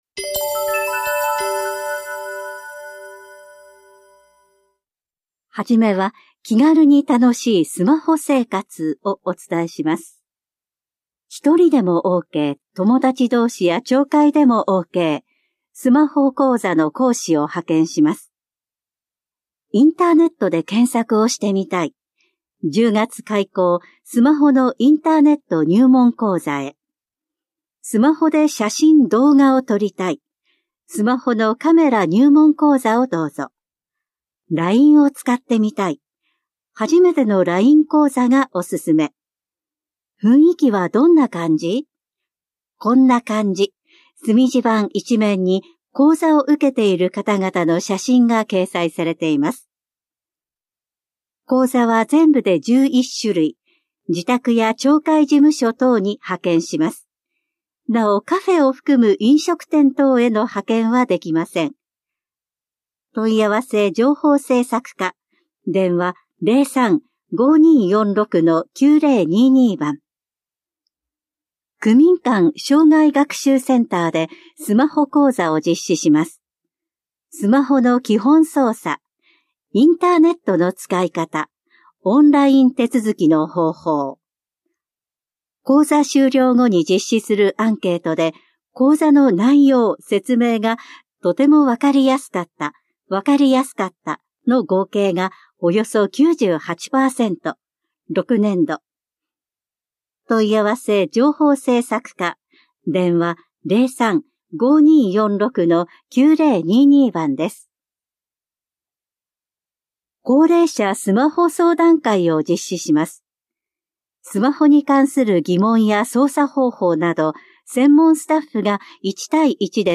広報「たいとう」令和6年9月5日号の音声読み上げデータです。